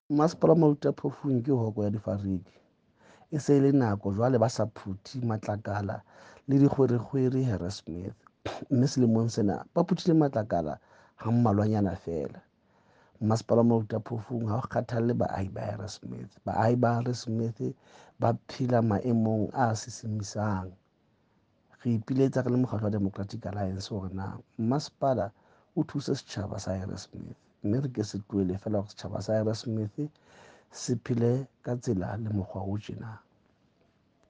Sesotho by Cllr Moshe Lefuma.
Sotho-voice-Moshe-2.mp3